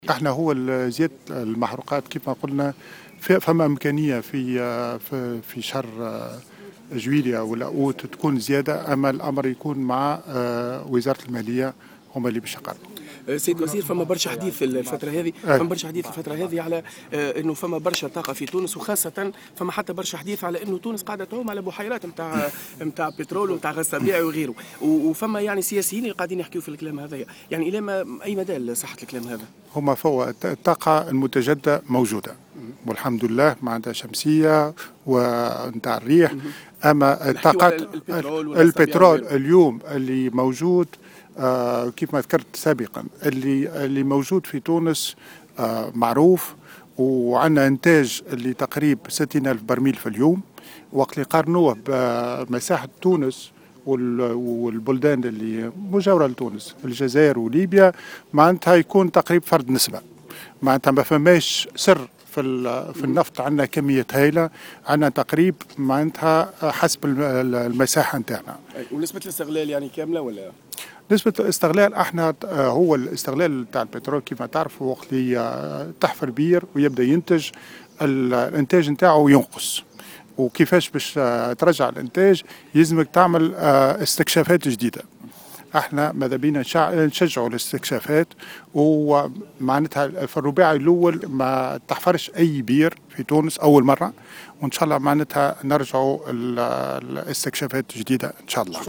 Le ministre de l’industrie, de l’énergie et des mines, Kamel Bennaser a indiqué, dans une déclaration accordée à Jawhara FM, mardi 29 avril 2014, en marge de sa participation à la clôture de la première session du forum des affaires à Sousse, que les prix des hydrocarbures pourraient être révisés à la hausse lors du mois de Juillet prochain, ou au plus tard en Août.